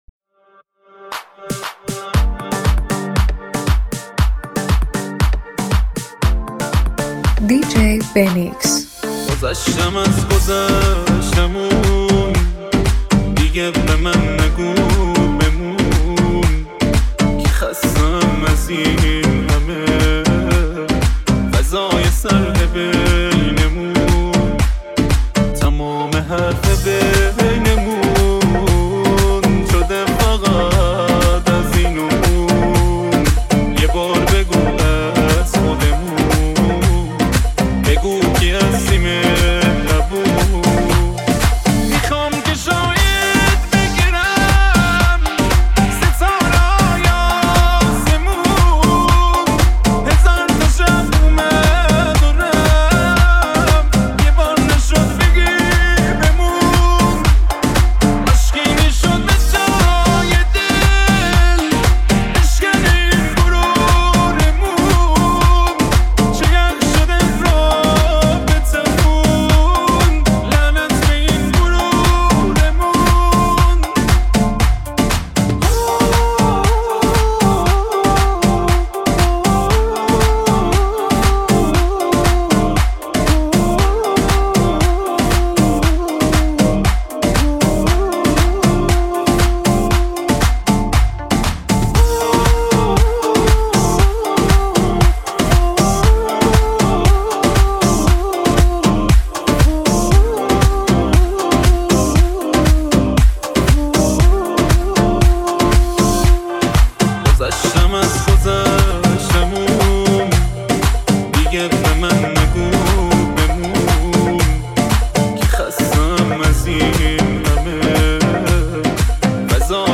ریمیکس شنیدنی و ریتمیک
آهنگ شاد و قوی